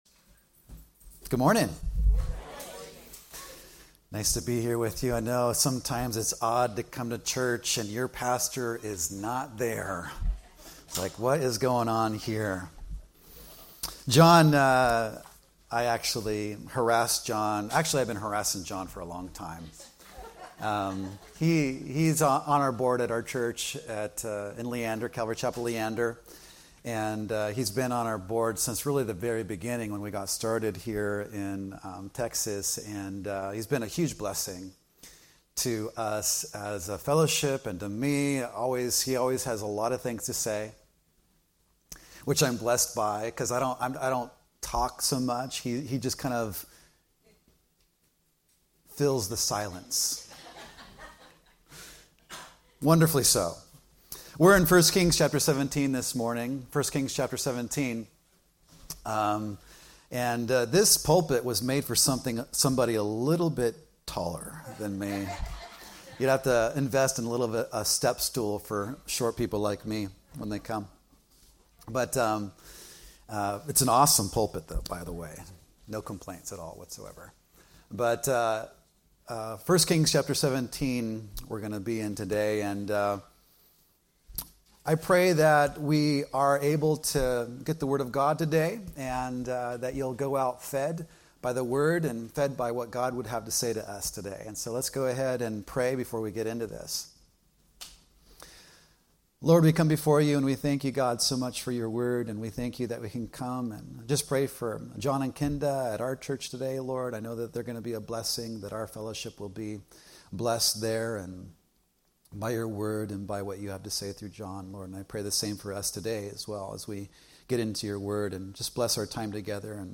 Guest Speakers
Messages